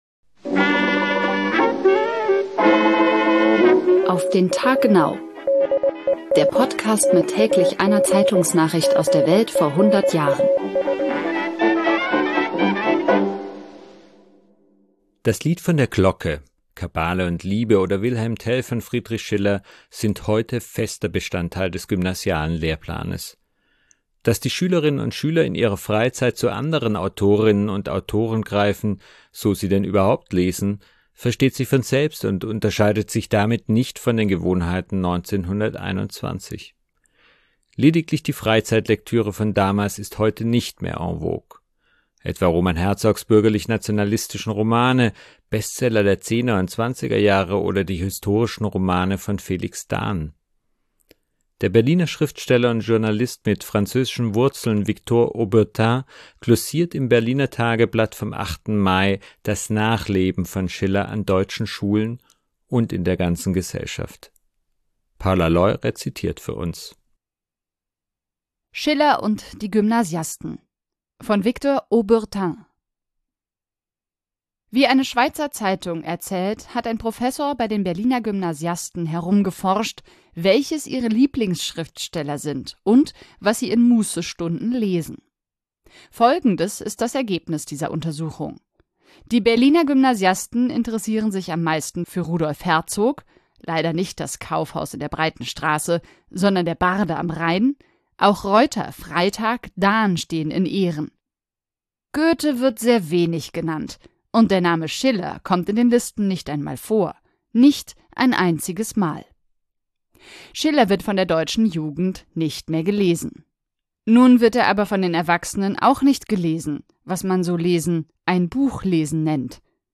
rezitiert für uns.